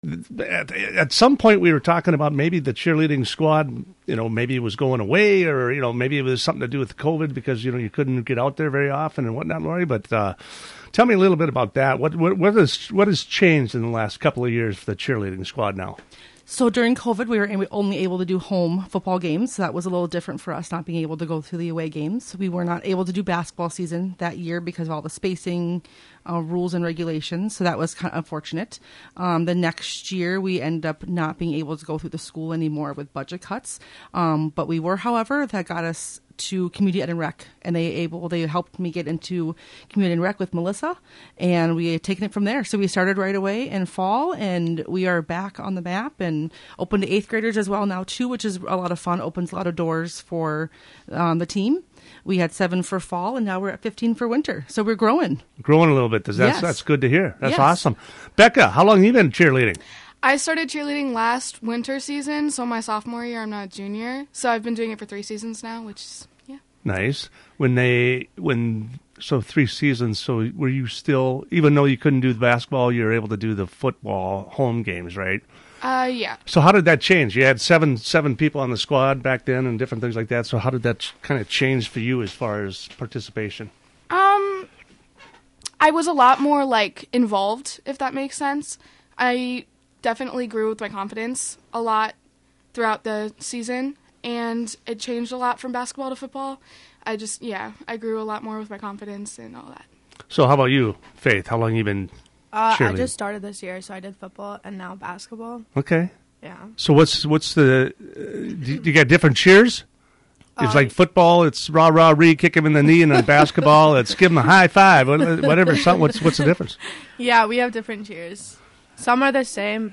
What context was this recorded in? The Cheerleaders are in studio this week.